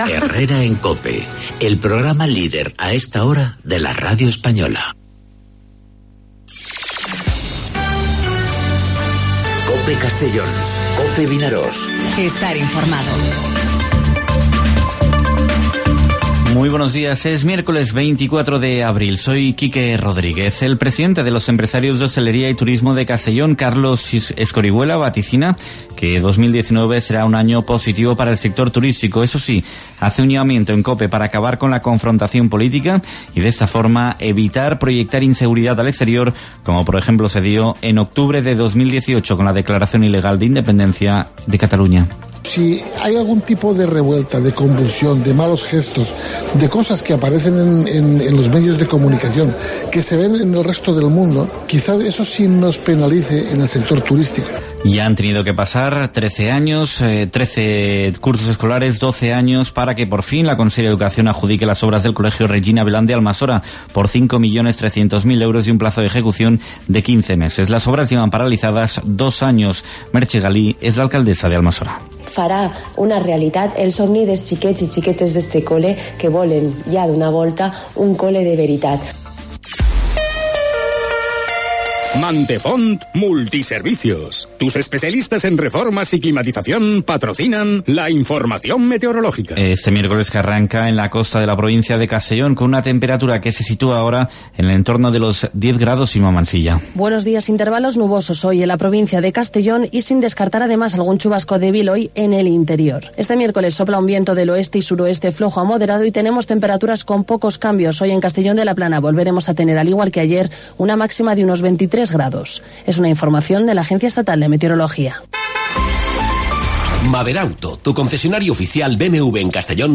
Informativo 'Herrera en COPE' Castellón (24/04/2019)